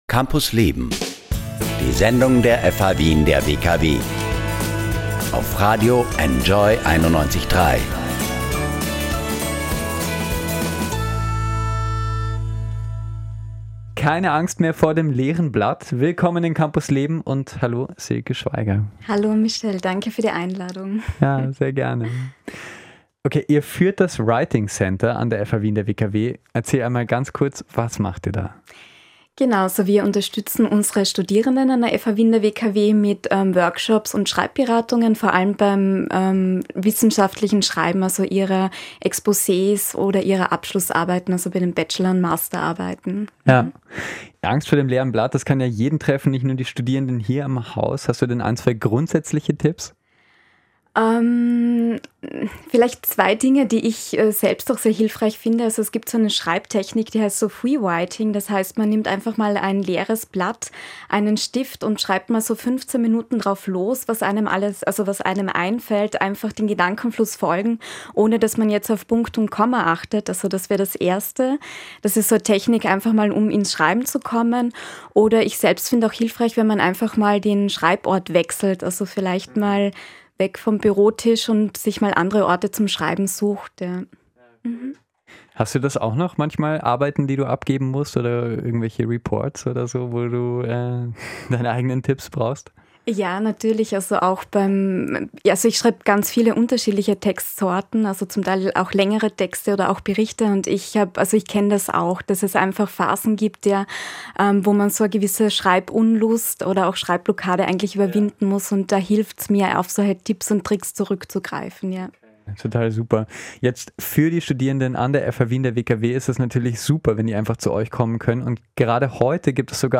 Radiointerviews